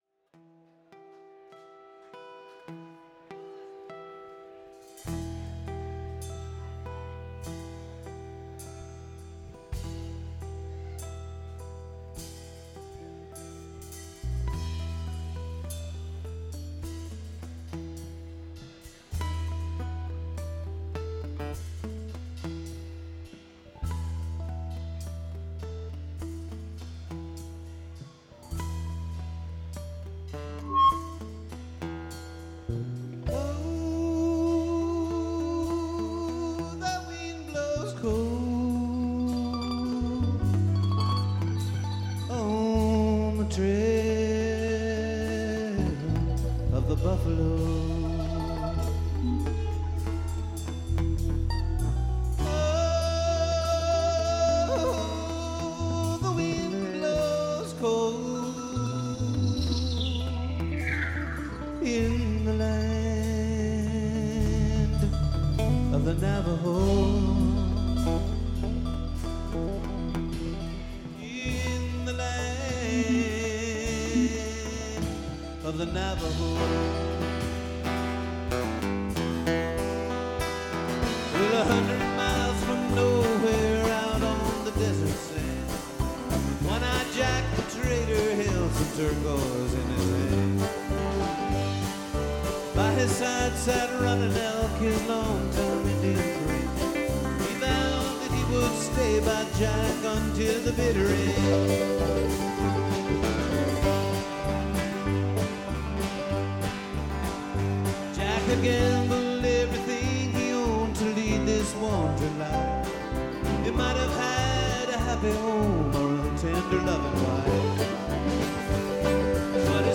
Fillmore West San Francisco, CA.